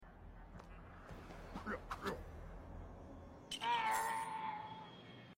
Yoda Old Death Sound sound effects free download
Yoda - Old Death Sound Mp3 Sound Effect